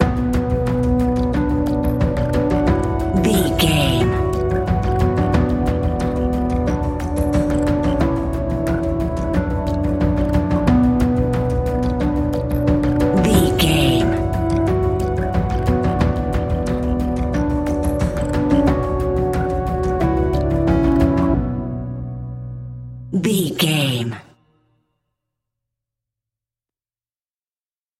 Aeolian/Minor
ominous
dark
haunting
eerie
synthesiser
drums
flute
horror music
horror instrumentals